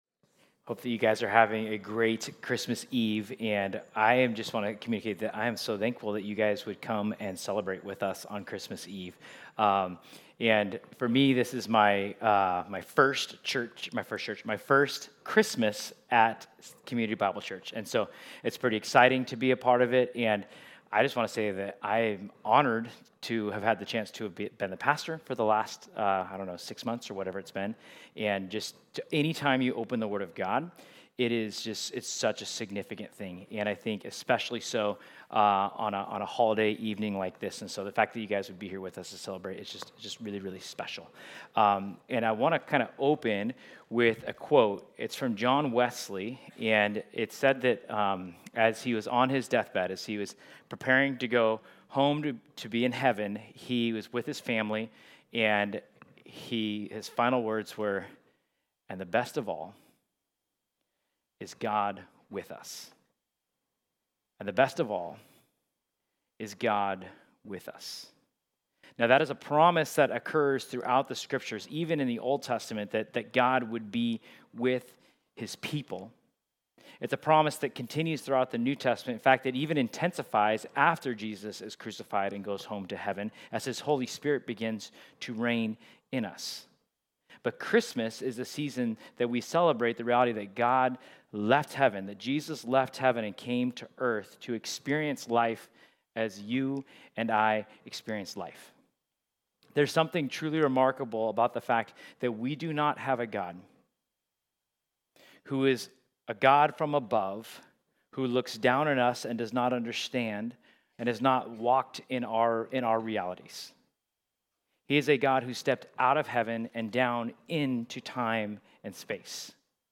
Christmas Eve Service – That All May Know